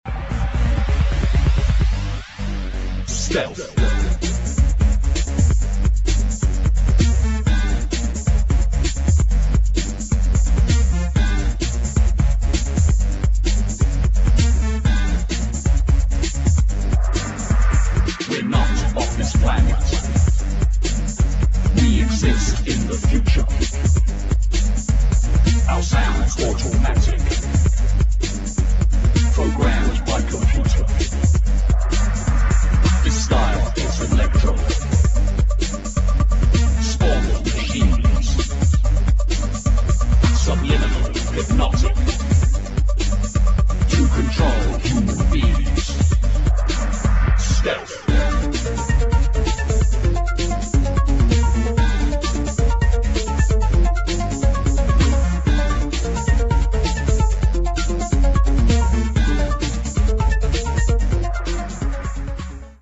[ TECHNO | TECH HOUSE ]